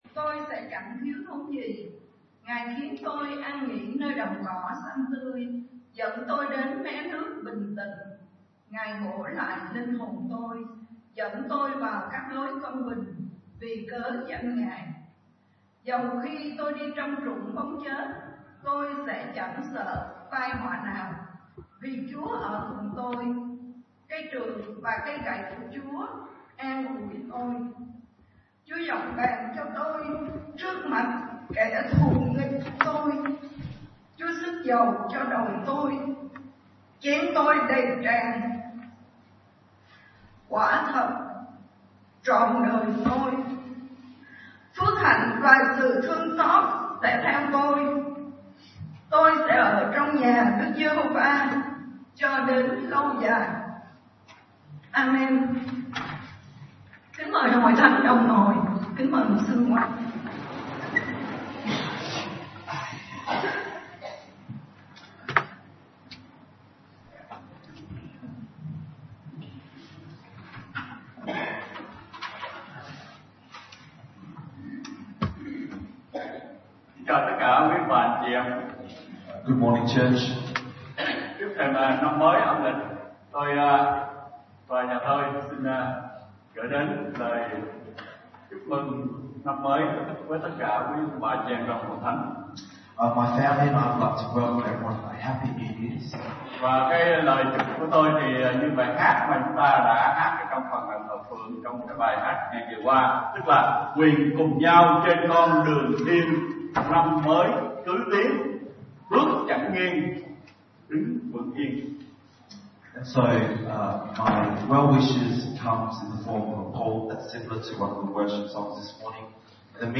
Bài Giảng , Tài liệu Sunday 19.1.2020 – Năm Mới Tôi Sẽ…